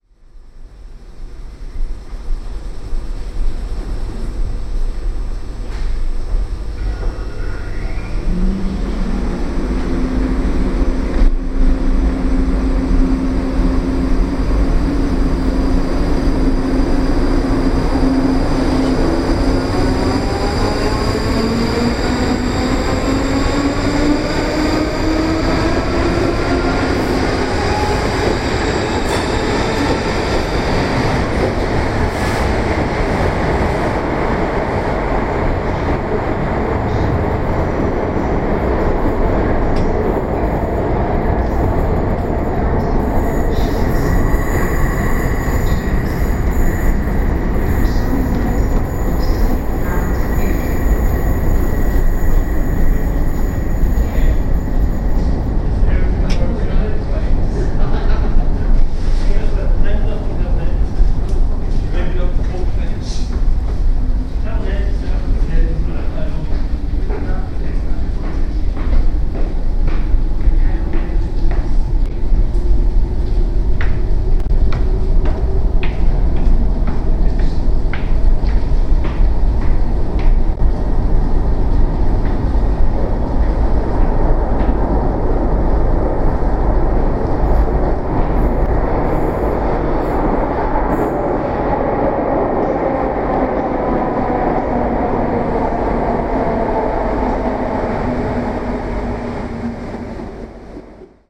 Waiting for the Airdrie train at High St station, Glasgow. Binaural stereo recording Mon 13 February 2012.